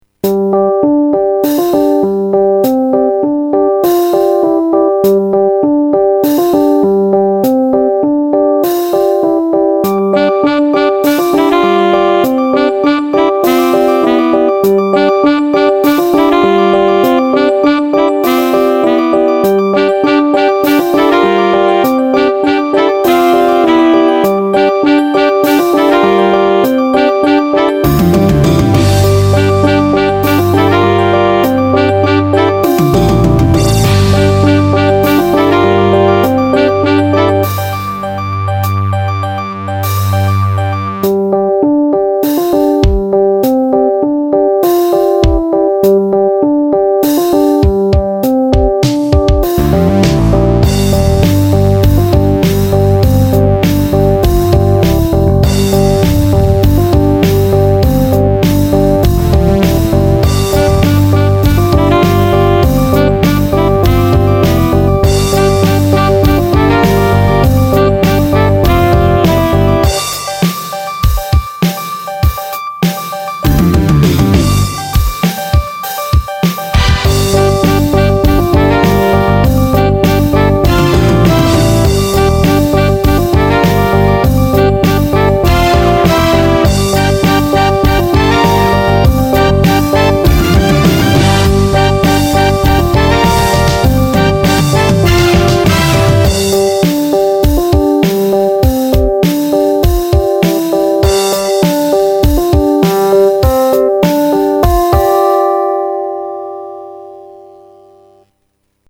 1枚目のＣＤ完成後、次作は、１曲目でアピールしようと、しっかり盛り上るように計算して書いた曲。